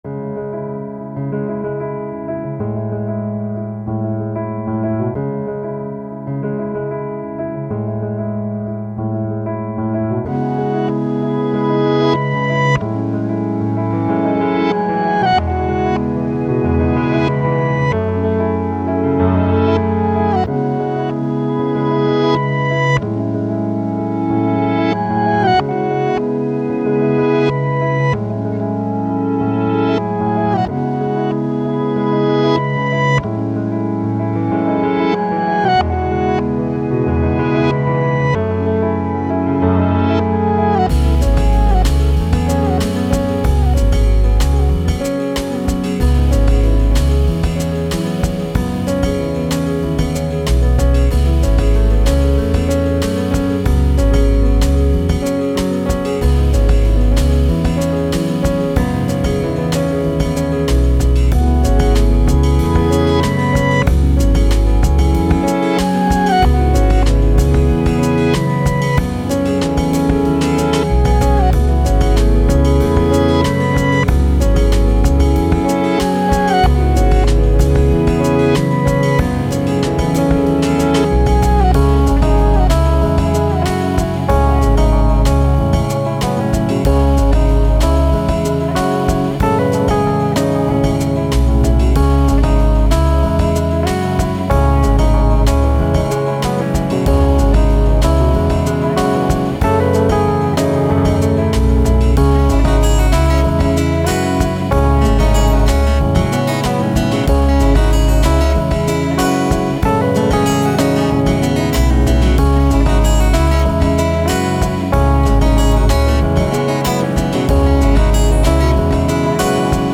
Soundtrack, Piano, Ambient, Downtempo, Thoughtful, Reverse